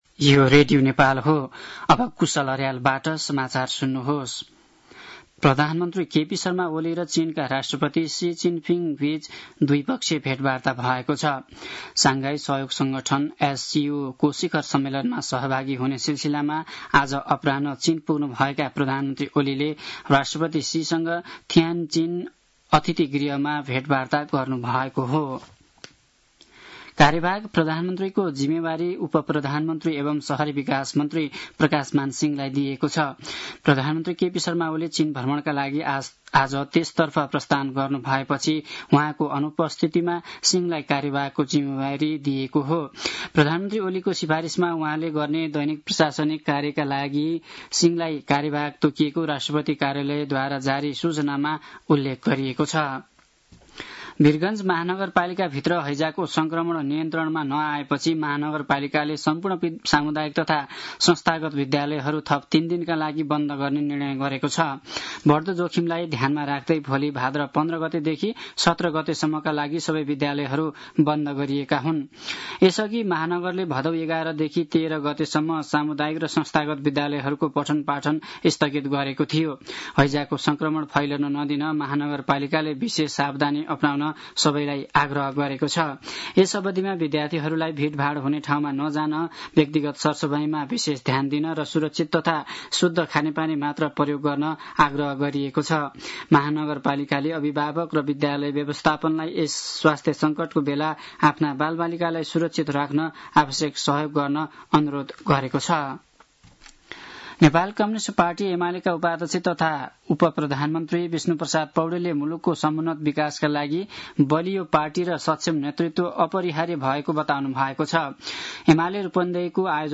साँझ ५ बजेको नेपाली समाचार : १४ भदौ , २०८२